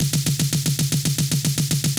If you turn the ‘play mode’ dial below the main window in the NNXTs editor panel you can set the sample to loop when you hold a key down.